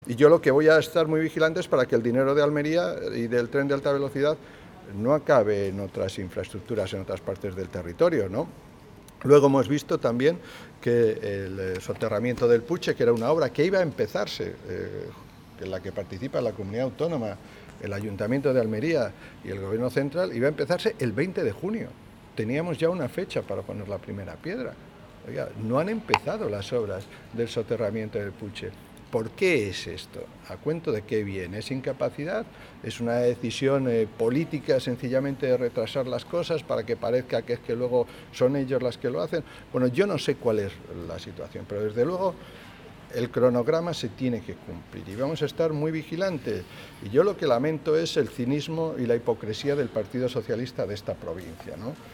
El portavoz adjunto del PP en el Congreso de los Diputados, Rafael Hernando, ha afirmado hoy en El Ejido que lo que está ocurriendo con los retrasos del AVE le recuerda mucho a lo que desgraciadamente pasó en la provincia en el año 2004 con la llegada al Gobierno de Zapatero, la derogación del trasvase del Ebro.